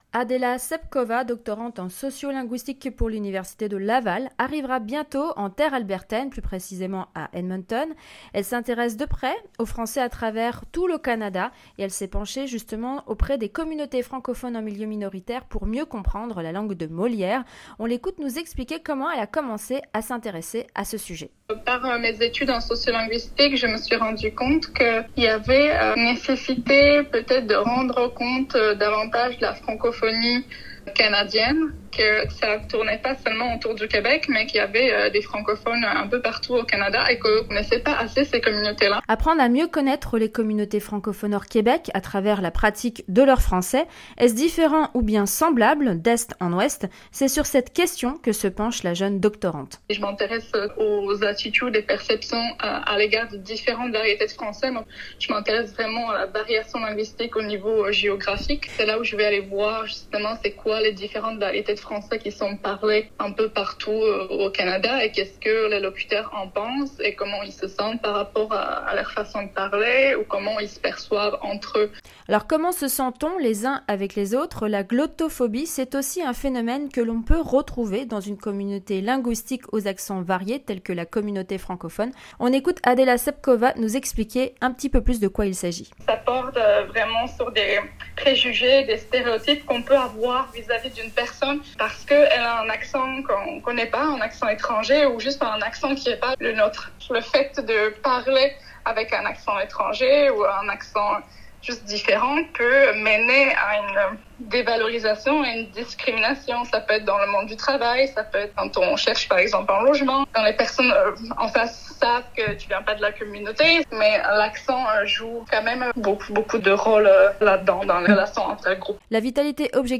Un reportage de notre journaliste